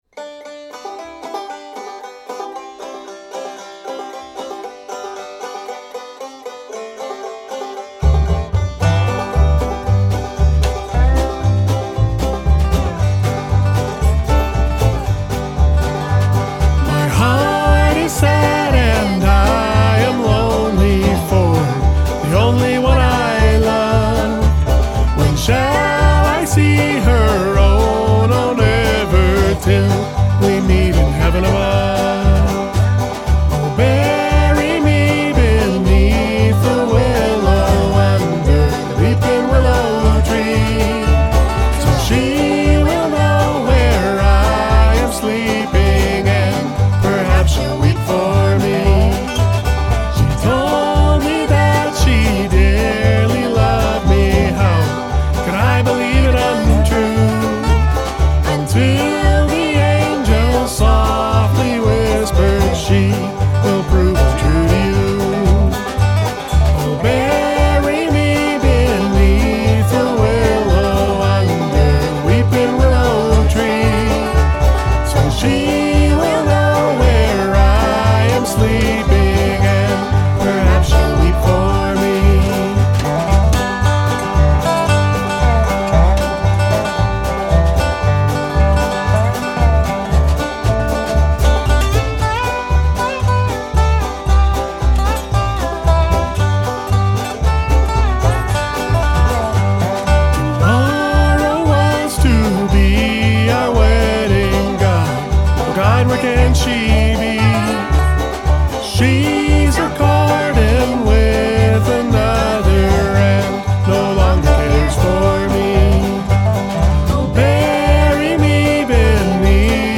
Upright bass
a traditional song performed by our community group